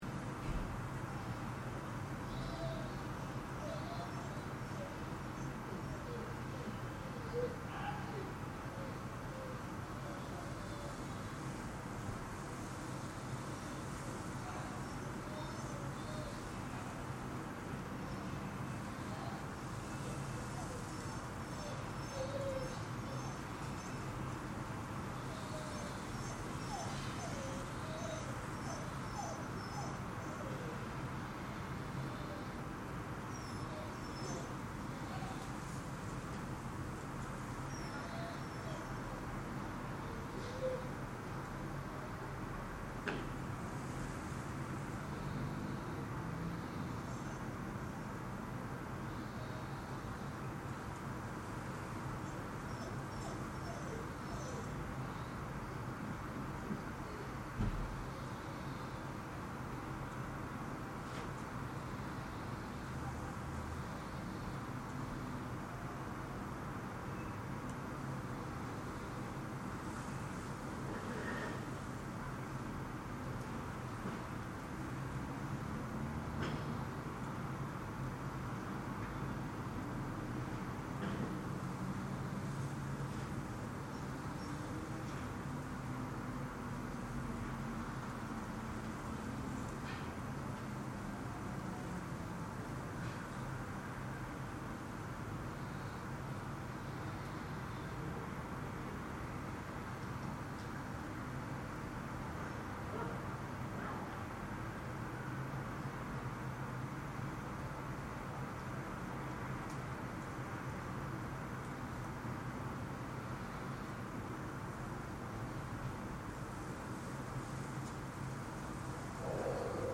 From my balcony in lockdown (Varaždin, Croatia)